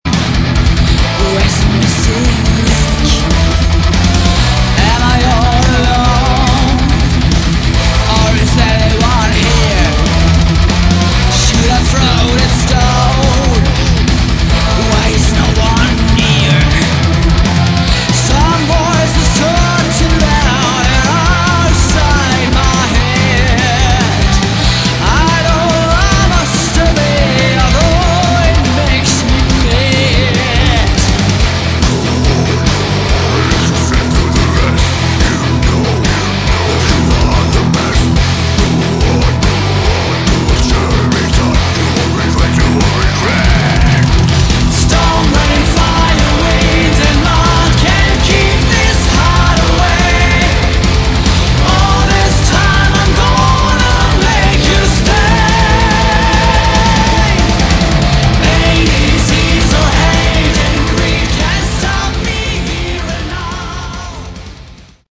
Category: Prog Rock/Metal
vocals
drums
guitar, bass, keyboards